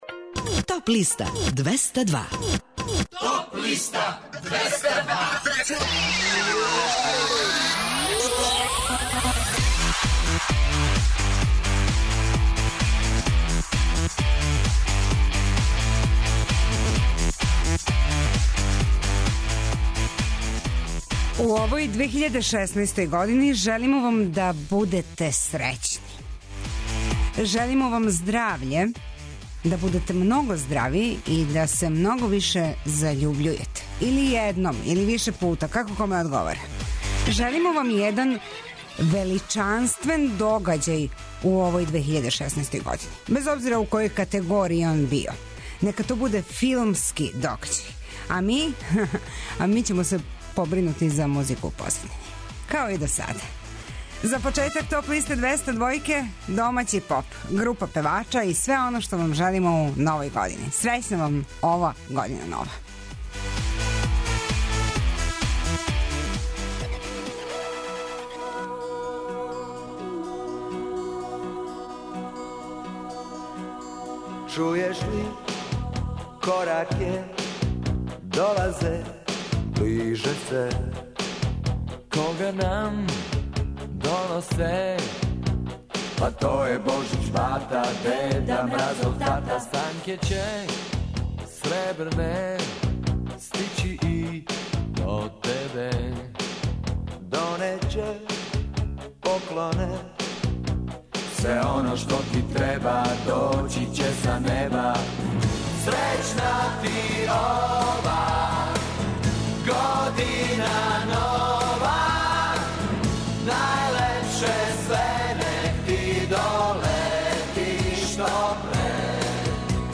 Слушаћемо их у категоријама: лектира, обраде, домаћи и страни поп, рок и етно.